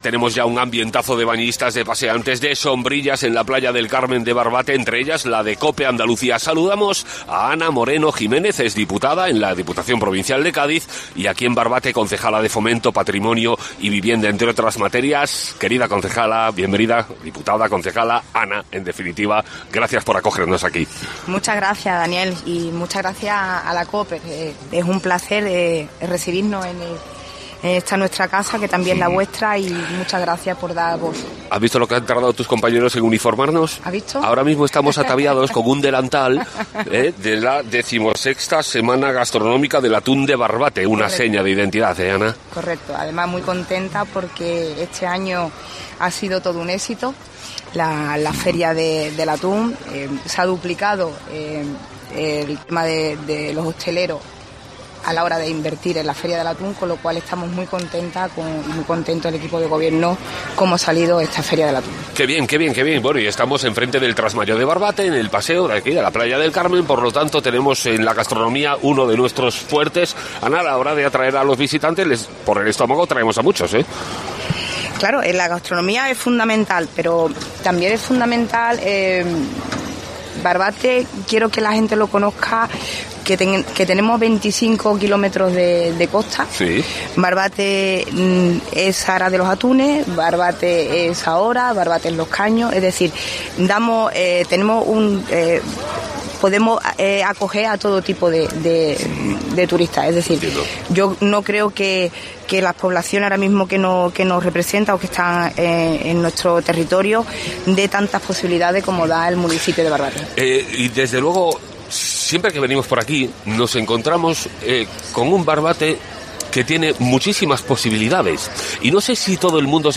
Entrevistamos a Ana Moreno, concejal de fomento del ayuntamiento de Barbate (Cádiz)